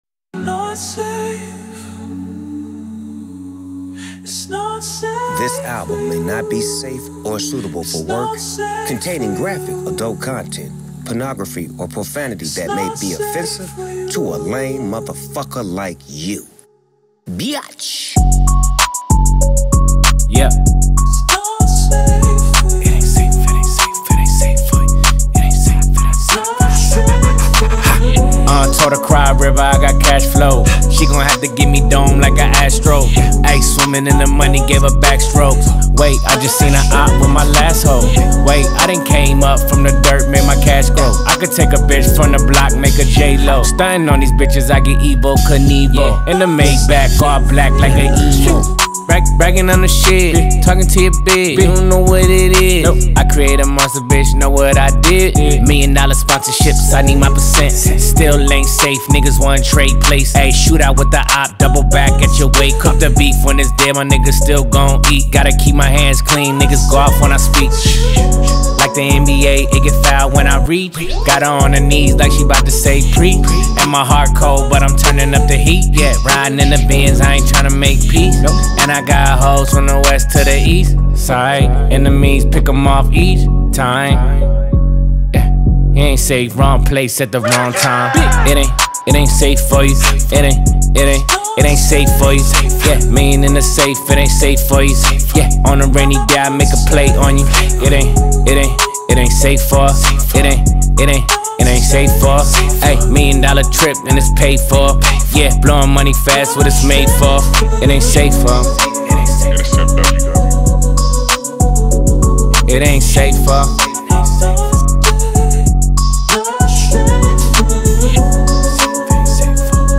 что значительно повысило его популярность в хип-хопе.